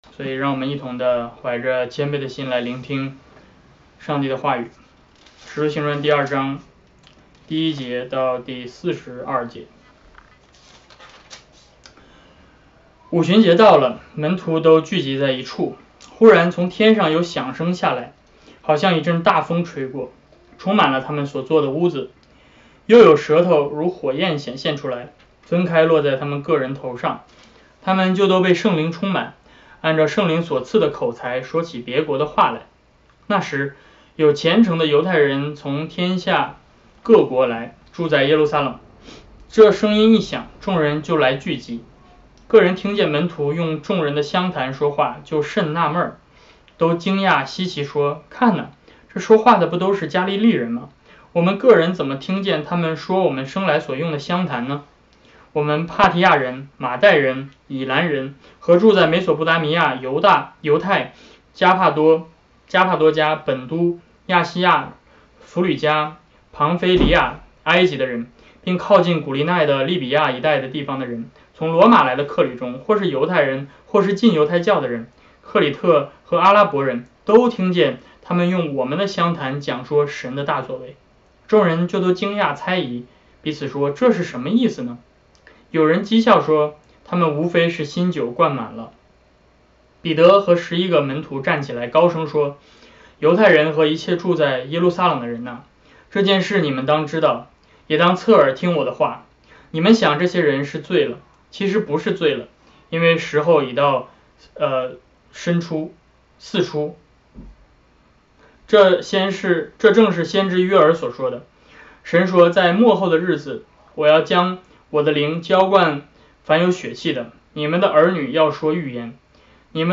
约珥书2:28-32 Service Type: 主日讲道 节期讲道 圣灵是谁？ 5 月 31, 2020 经文：使徒行传2：1-42 标题：圣灵是谁 要点： 圣灵是创…